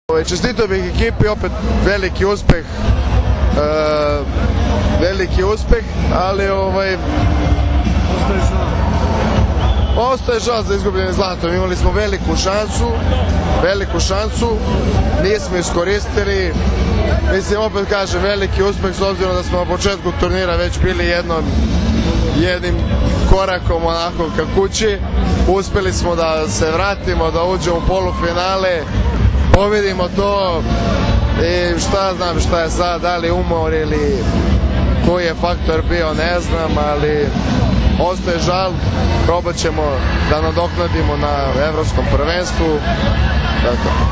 IZJAVA NIKOLE KOVAČEVIĆA